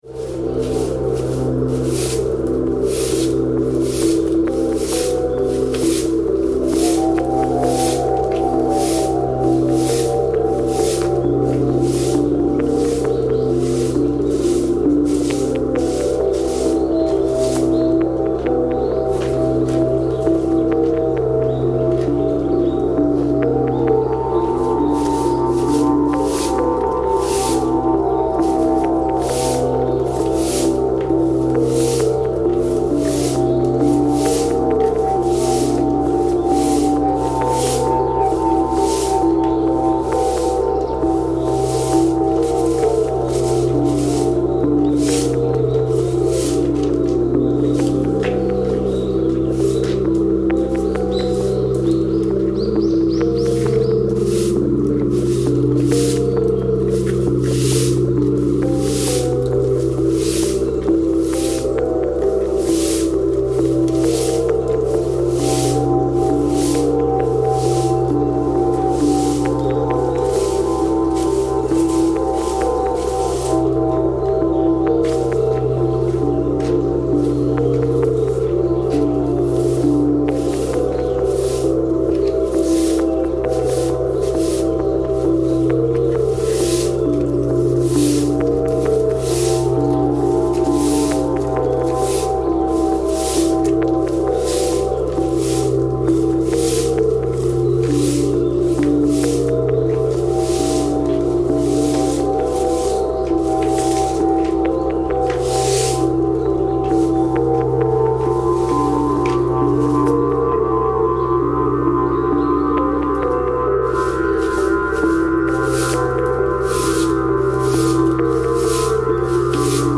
Nara shrine reimagined